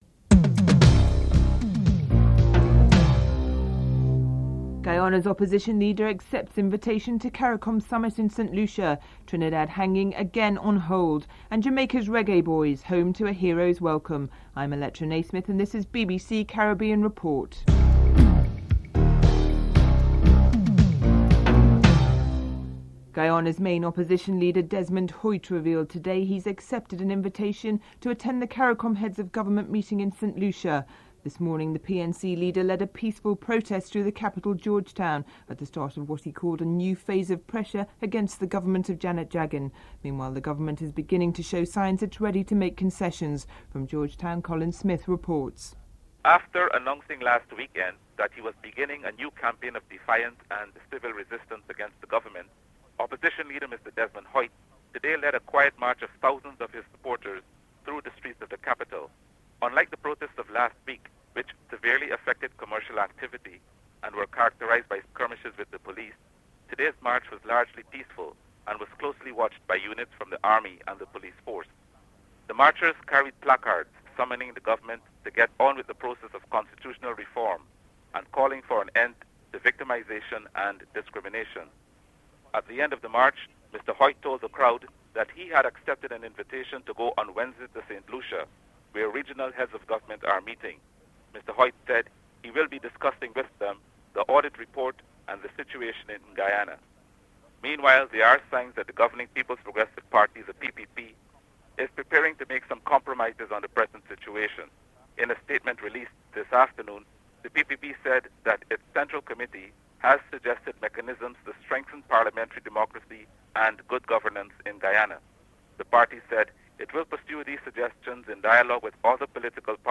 Prime Minister Keith Mitchell comments (02:06-04:52)